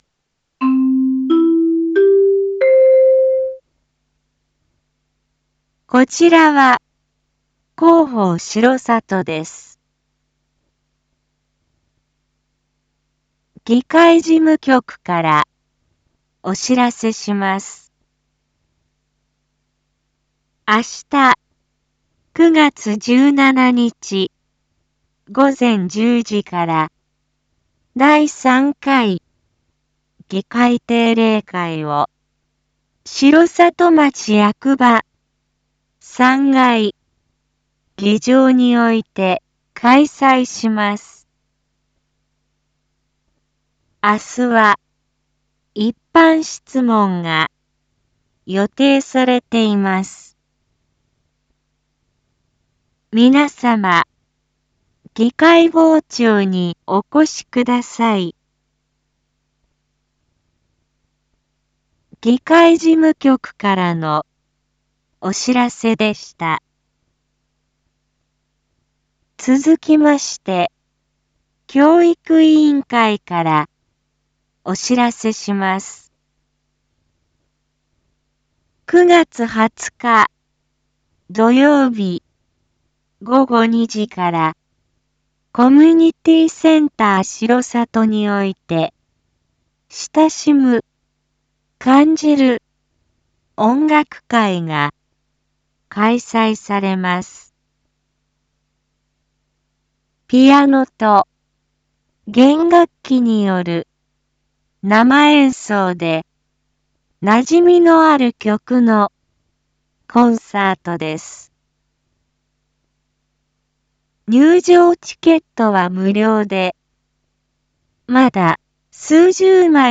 一般放送情報
Back Home 一般放送情報 音声放送 再生 一般放送情報 登録日時：2025-09-16 19:02:37 タイトル：第3回議会定例会 親しむ・感じる音楽会 インフォメーション：こちらは広報しろさとです。